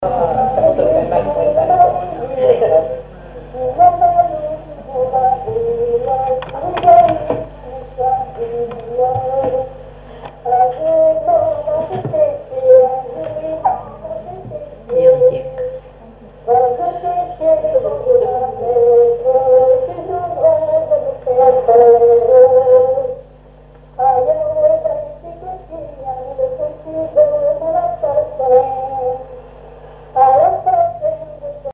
Informateur(s) Collectif épinérien groupe
chansons enregistrées sur un magnétophone à cassettes
Pièce musicale inédite